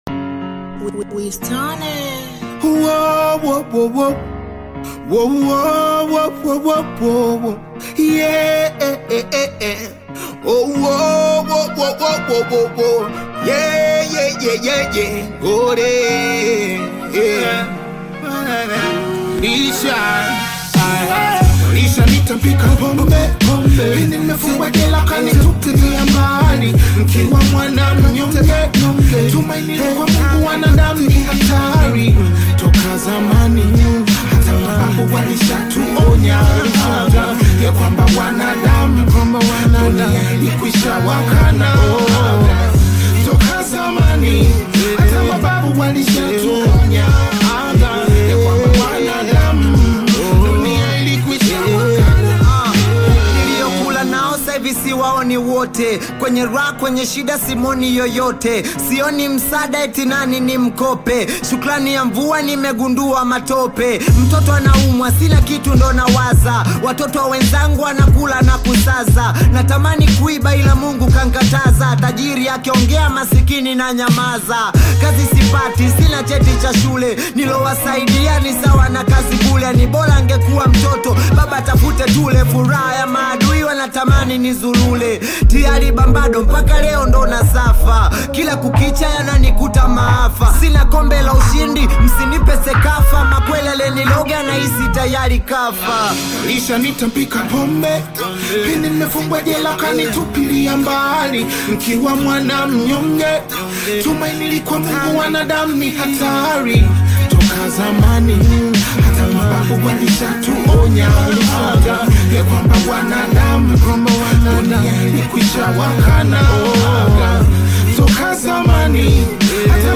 Tanzanian bongo flava artist singer
African Music